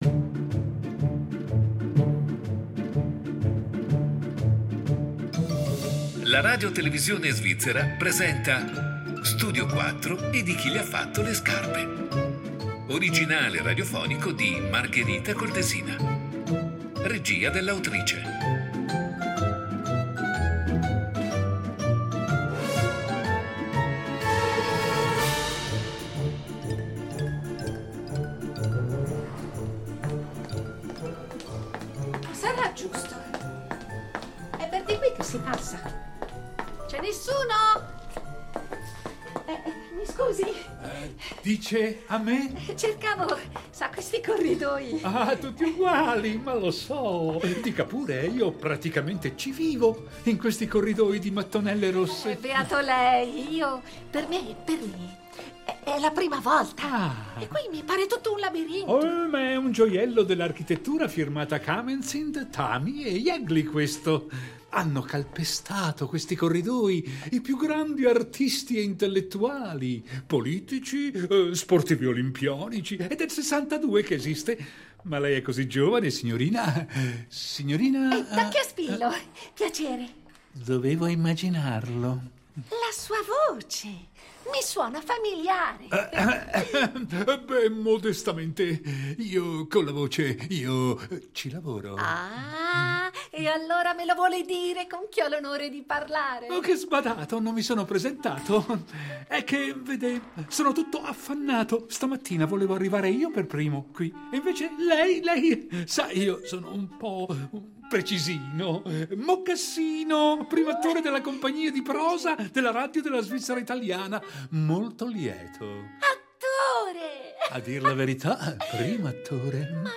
Lo Studio 4 va in pensione, ok, e io ho il magone, tutti noi, ma chi meglio delle voci storiche della Compagnia di Prosa della Radiotelevisione svizzera perché sia un degno congedo?